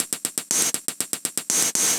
Index of /musicradar/ultimate-hihat-samples/120bpm
UHH_ElectroHatA_120-03.wav